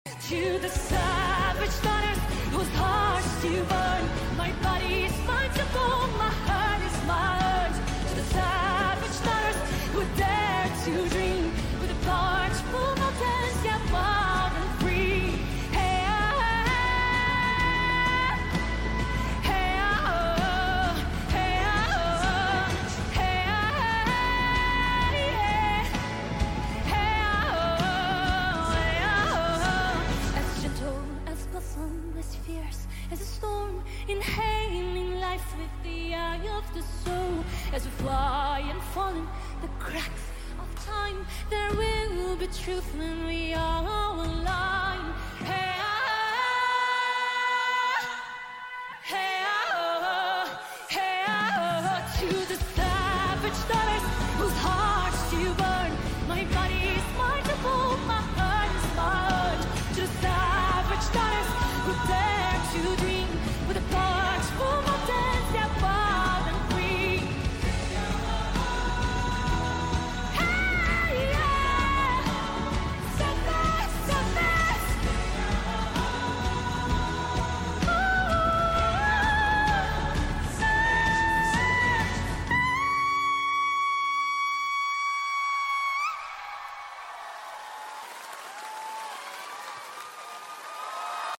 in a live show